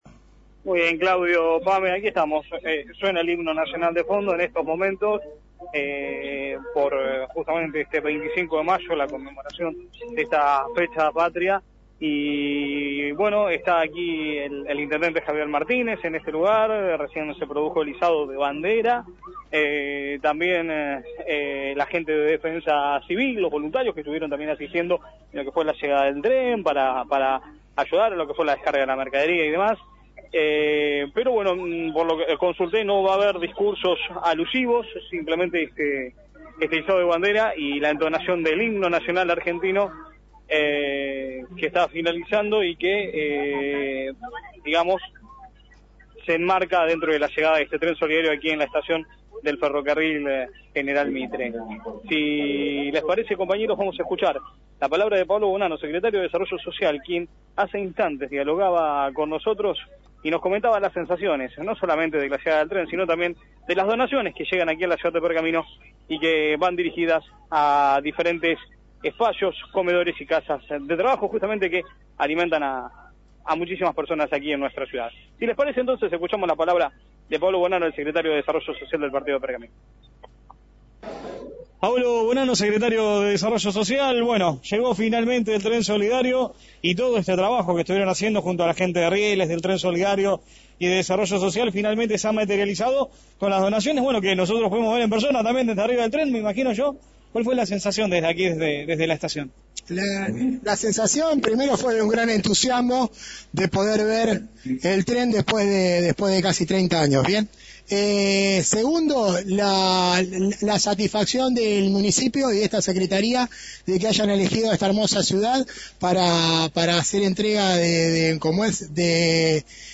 Tras el recibimiento, diálogo con el móvil de «La Mañana de la Radio» y esto decía: